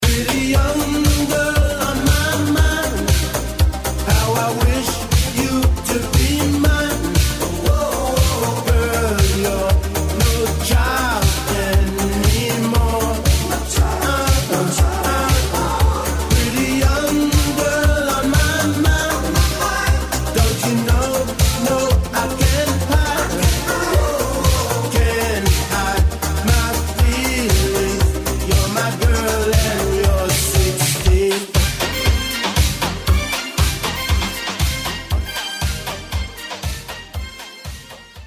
жанр, категория рингтона ЖАНР: РИНГТОНЫ 80е-90е